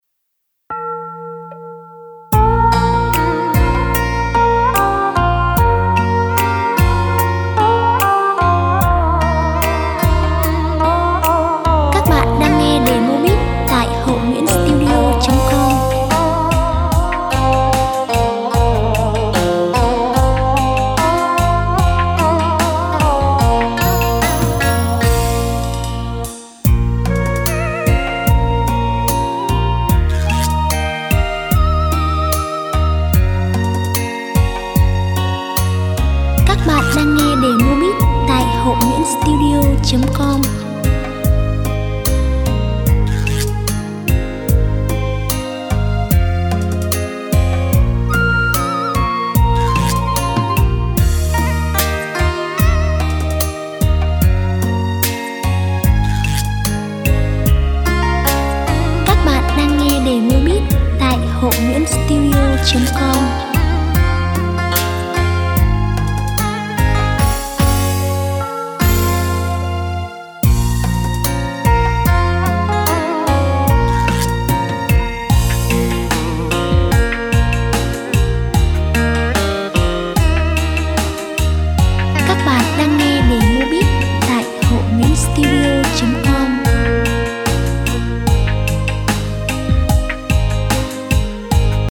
thuộc thể loại Beat